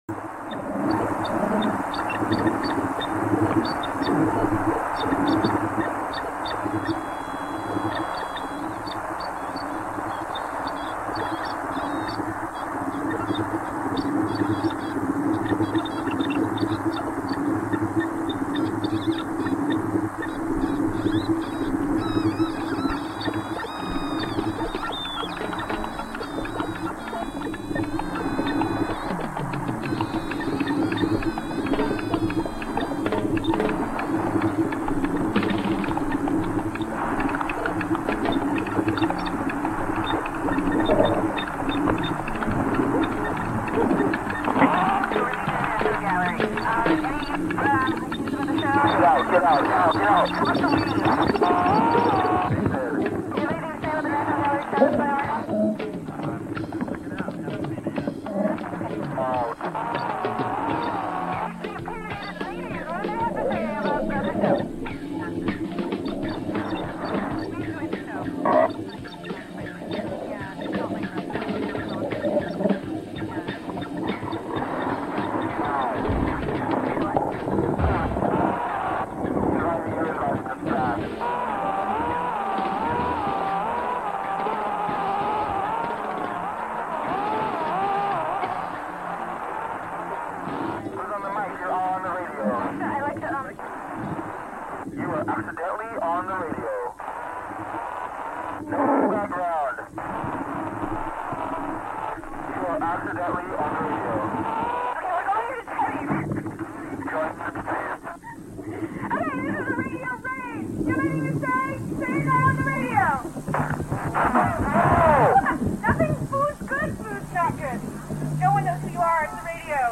Live Phone in Report for Live From Momenta Art (Audio)